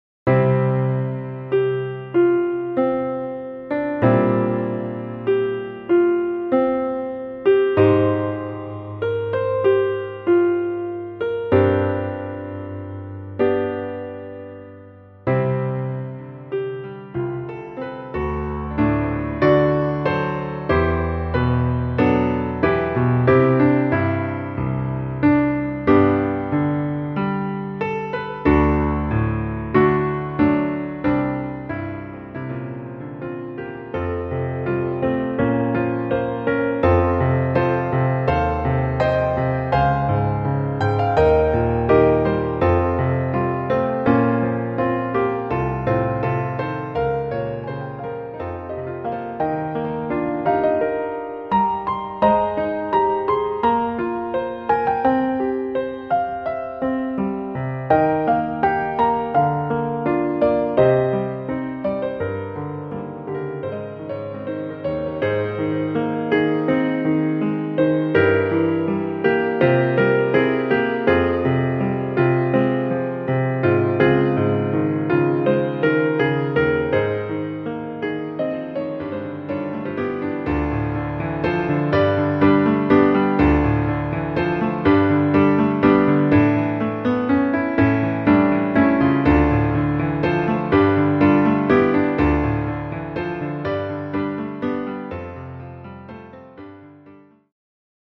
試聴用クロスフェードデモはこちら
その最後の一日を描いたピアノソロアルバムです。